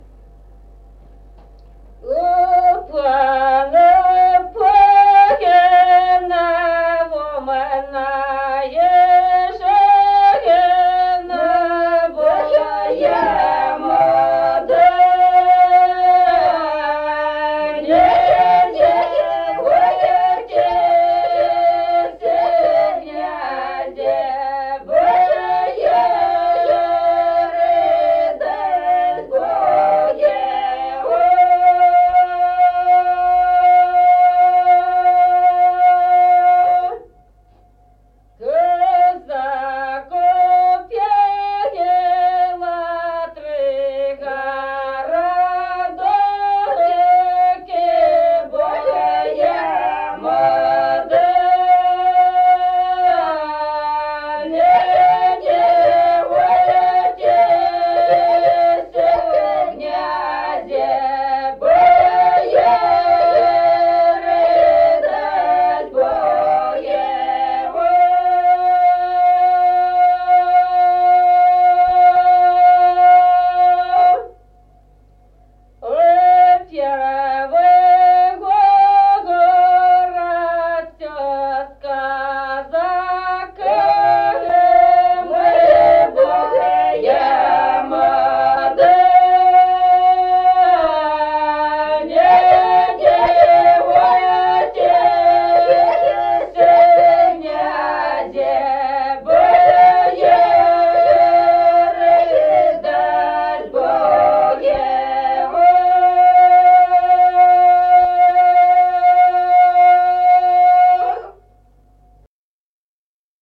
Народные песни Стародубского района «У пана, пана», новогодняя щедровная.
с. Остроглядово.